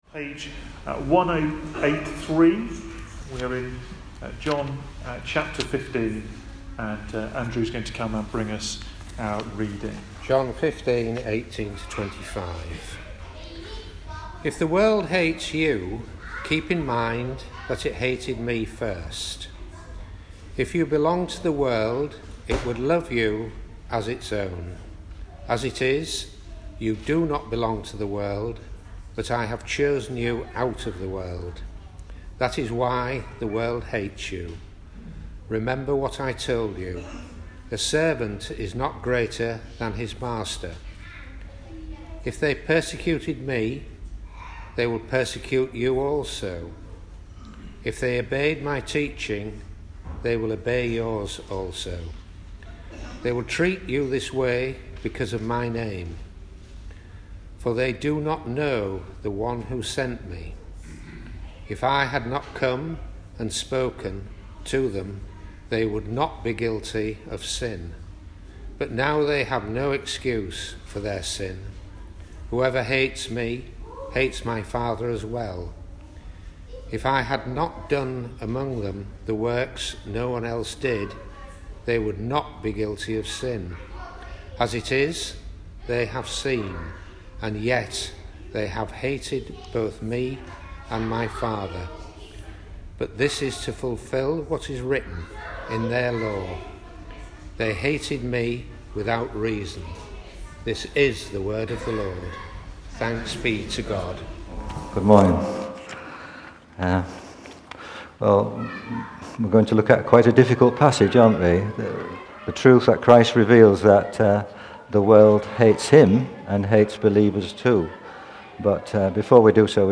Media Library We record sermons from our Morning Prayer, Holy Communion and Evening services, which are available to stream or download below.
Media for Morning Service on Sun 09th Oct 2022 10:45 Speaker